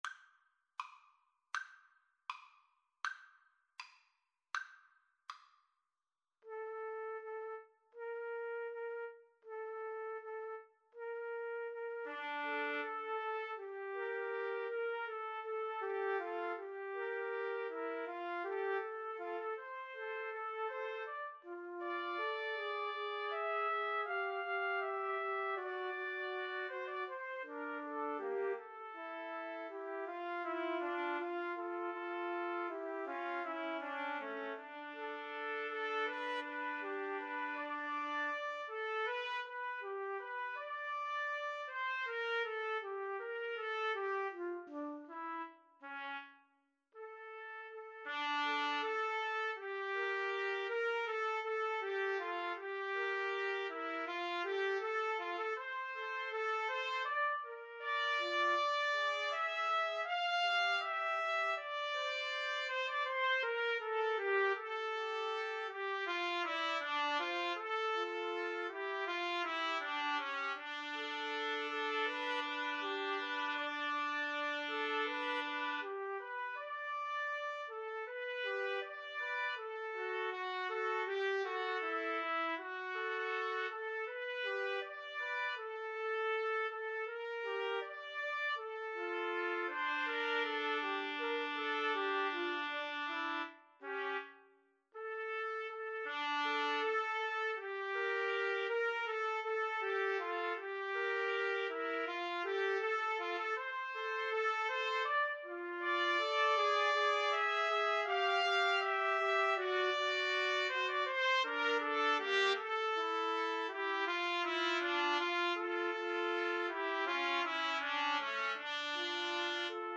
~ = 100 Andante
Classical (View more Classical Trumpet Trio Music)